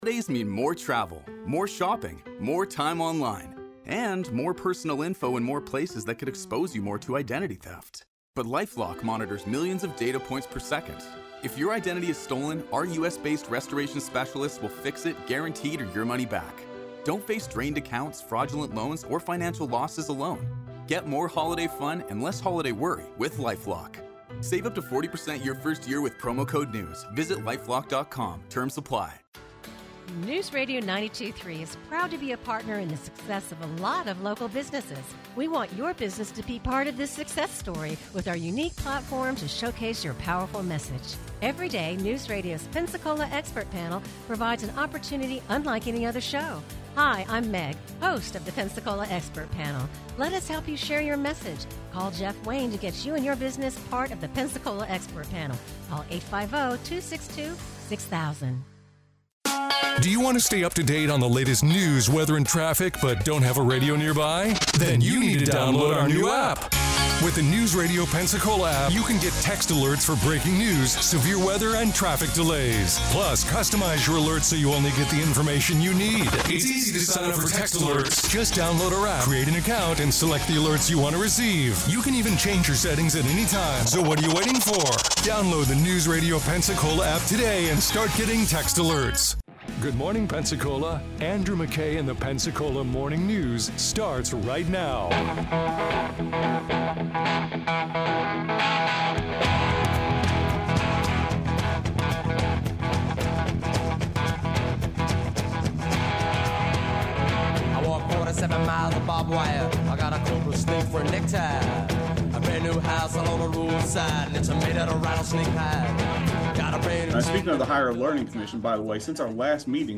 UWF Board of Trustees meeting, interview